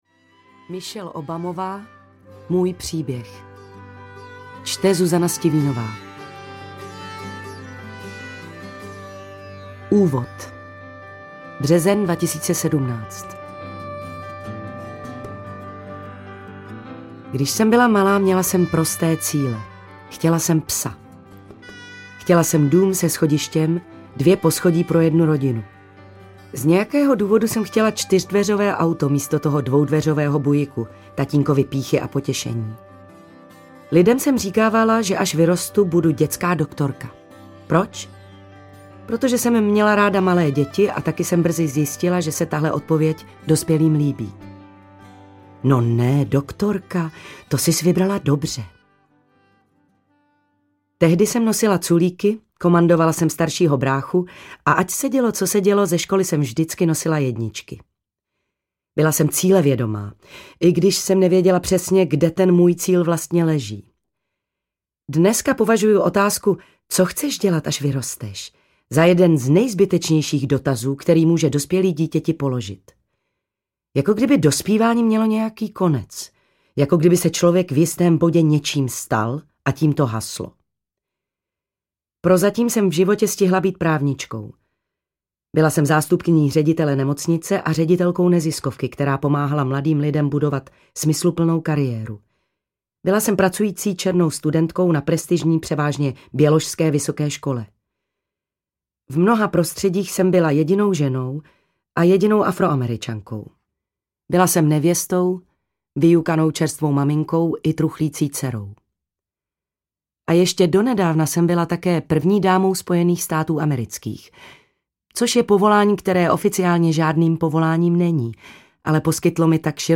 Můj příběh audiokniha
Ve zvukové podobě se interpretce Zuzaně Stivínové podařilo stát se jakoby alter egem autorky, takže s nebývalou přesvědčivostí a invencí vypráví její životní příběh.
• InterpretZuzana Stivínová